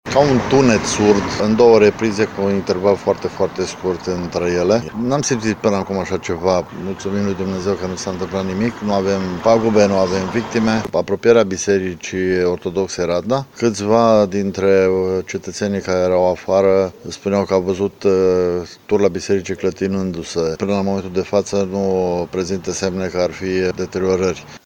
Și in orașul Lipova, aflat la 12 km de epicentru primului cutremur, cele 2 seiseme s-au simțit puternic, spune primarul Florin Pera.
b.-primar-Lipova.mp3